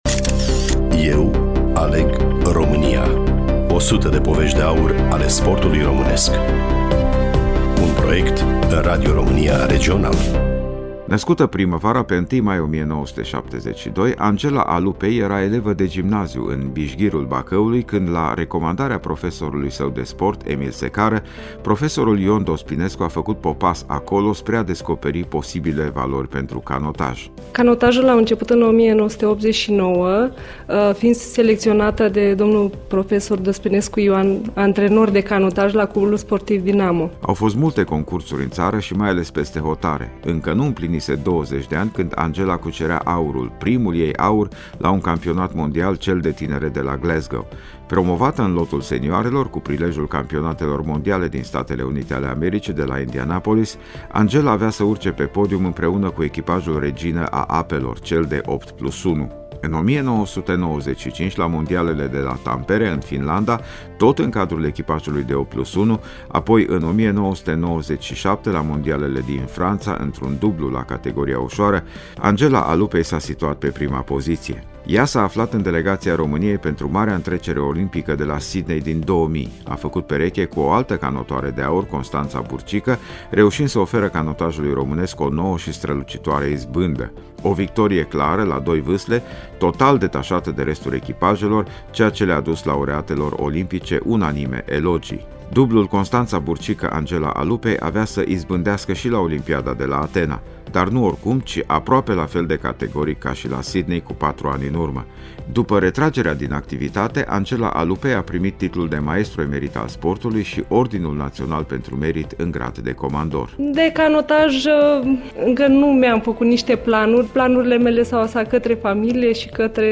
Studioul Regional Radio România Cluj